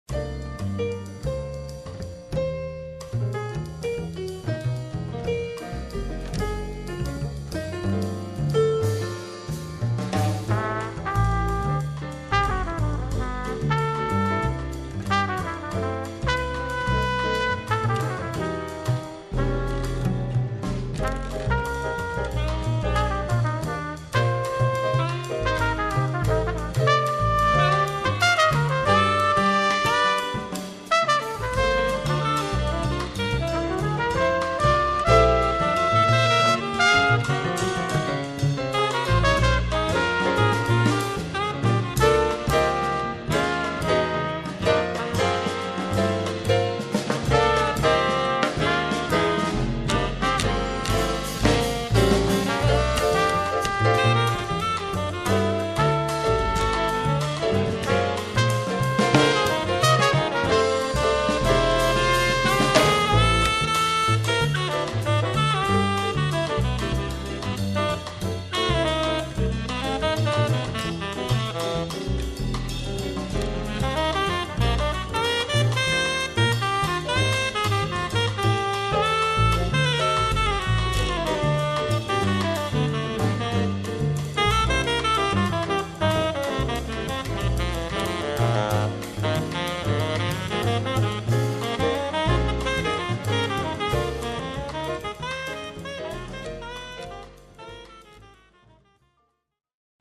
Velika nagrada za najboljšo slovensko jazz kompozicijo 2005.
saksofon
trobenta
klavir
kontrabas
bobni
Posneto: 19. avgust 2005, KC Janeza Trdine, Novo mesto